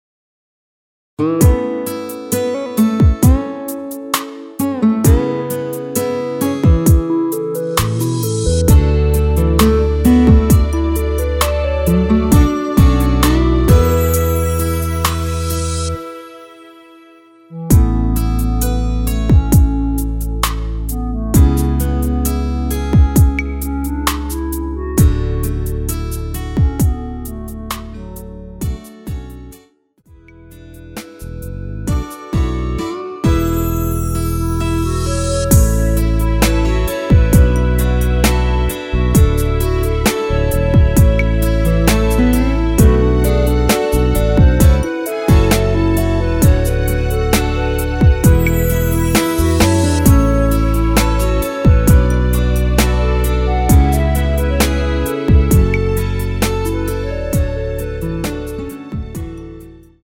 원키에서(-5)내린 멜로디 포함된 MR입니다.
앞부분30초, 뒷부분30초씩 편집해서 올려 드리고 있습니다.
중간에 음이 끈어지고 다시 나오는 이유는